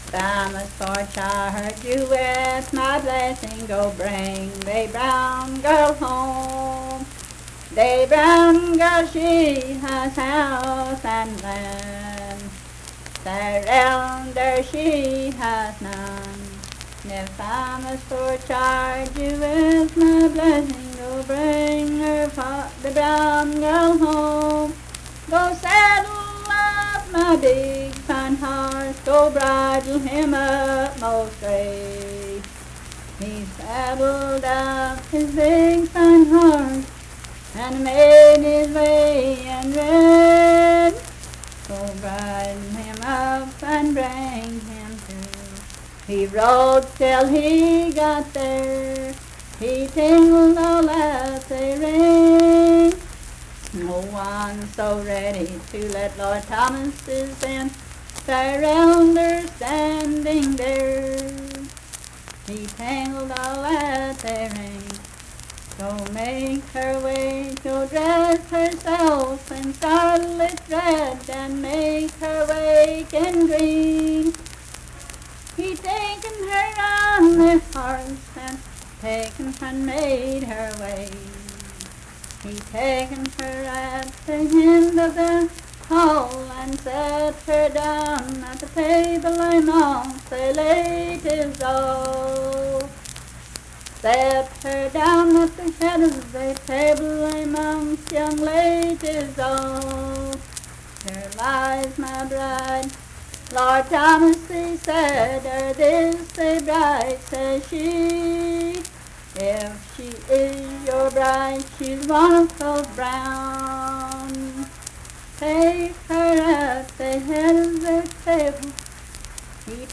Unaccompanied vocal music
Verse-refrain 7(3-8).
Performed in Big Creek, Logan County, WV.
Voice (sung)